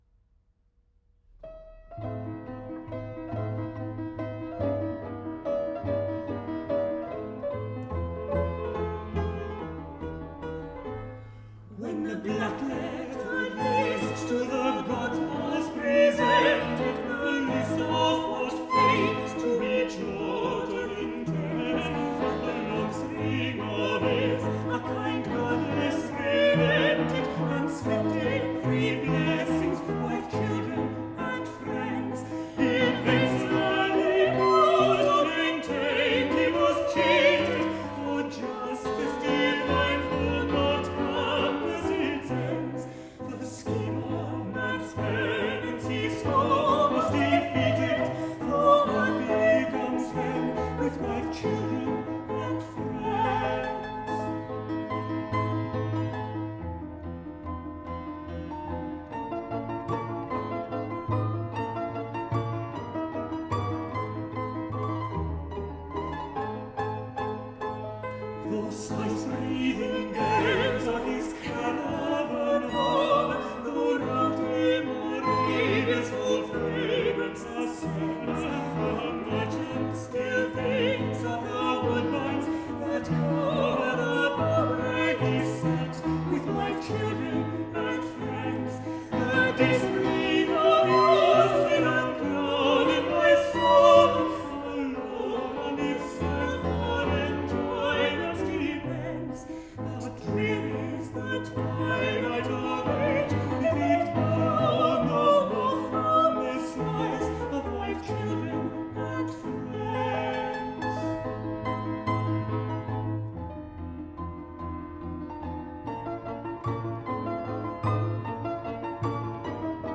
També recull cançons irlandeses, celtes i escoceses i en fa arranjaments per a veu (1, 2 i tres veus solistes) amb acompanyament de piano, violí i violoncel.
Wife, Children and friends (cançó irlandesa):